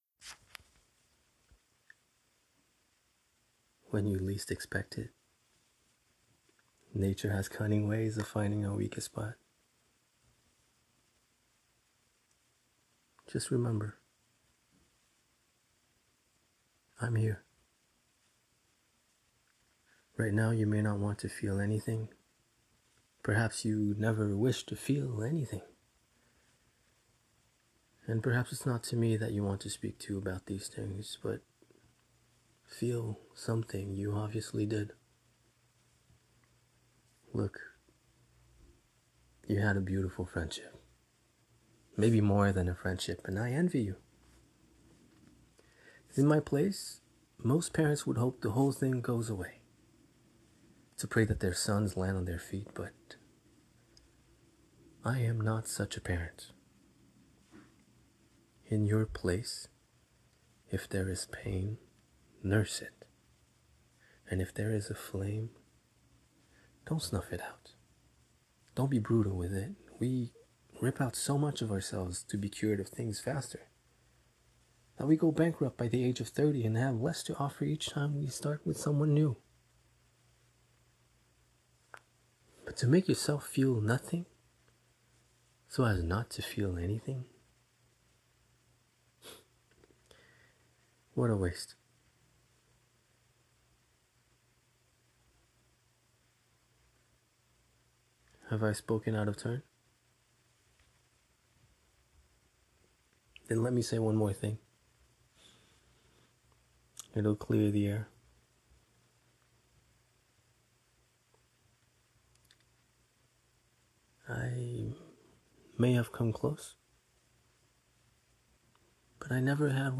Narrator (English)
18-30 ans ⸱ 30-45 ans ⸱ 45-60 ans ⸱ Narration ⸱ Publicité